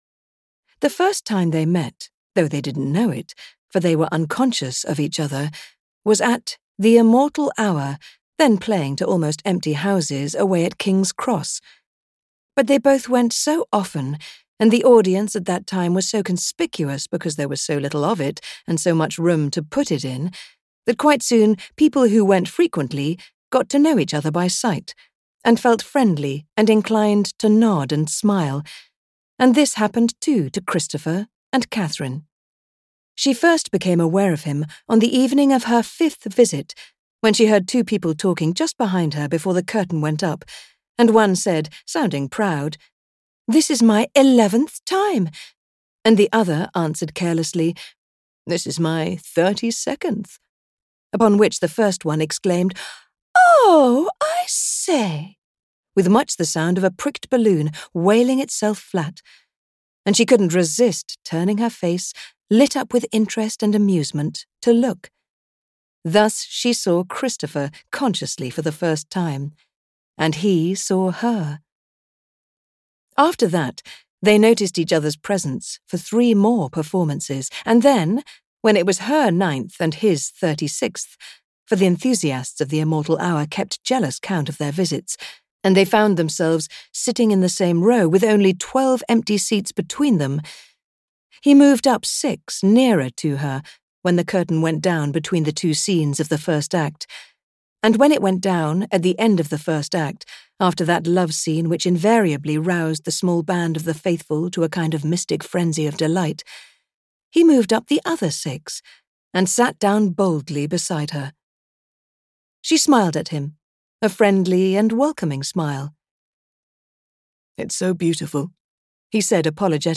Love audiokniha
Ukázka z knihy